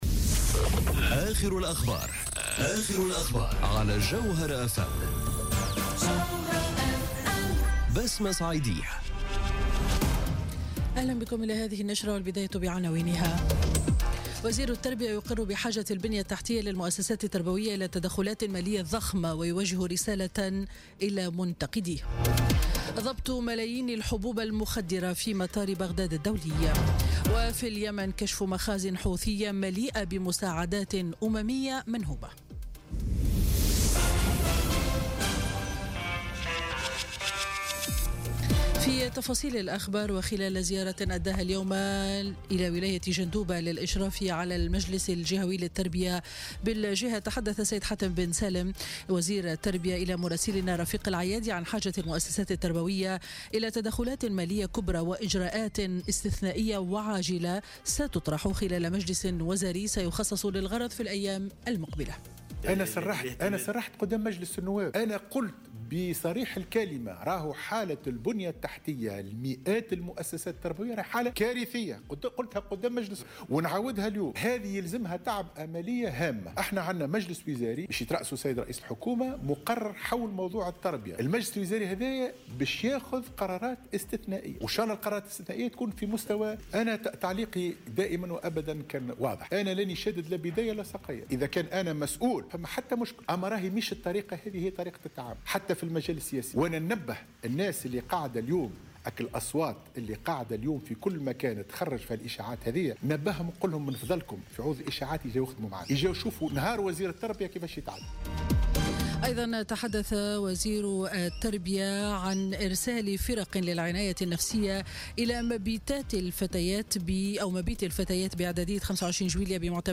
نشرة أخبار منتصف النهار ليوم الجمعة 9 فيفري 2018